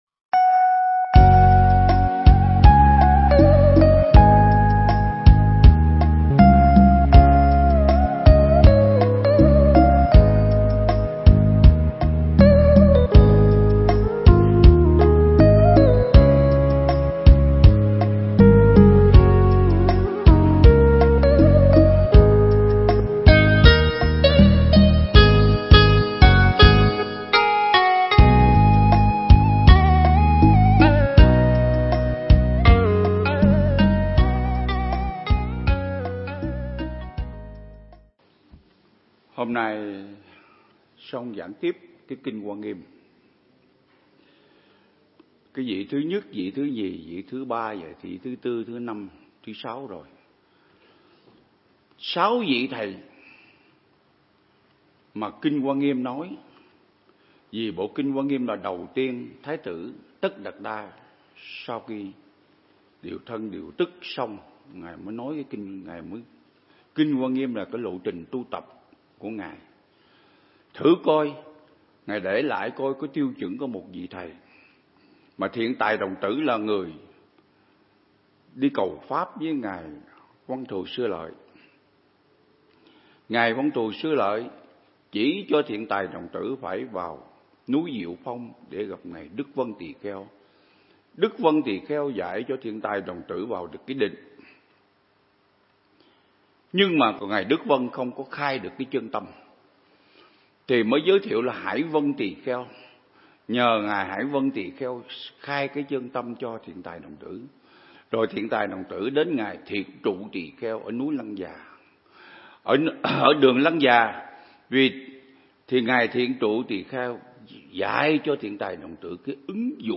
Mp3 Pháp Thoại Ứng Dụng Triết Lý Hoa Nghiêm Phần 35
giảng tại Viện Nghiên Cứu Và Ứng Dụng Buddha Yoga Việt Nam (TP Đà Lạt)